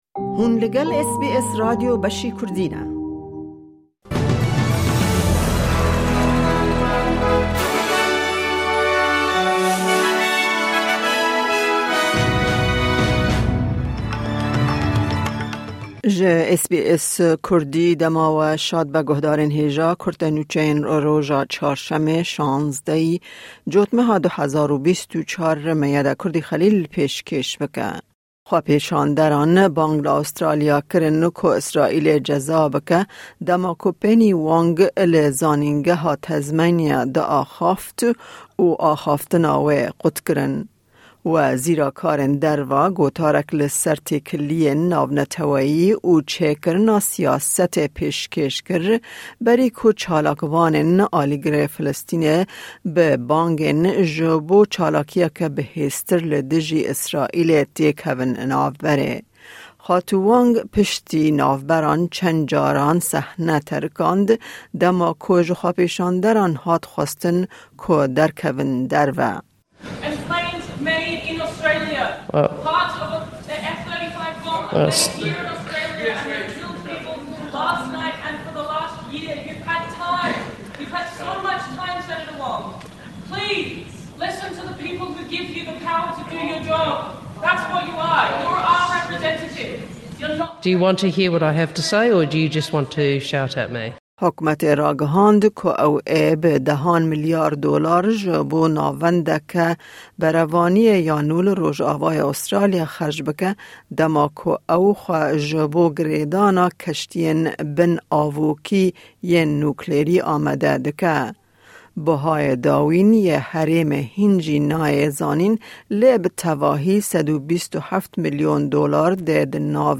Kurte Nûçeyên roja Çarşemê 16î Cotmeha 2024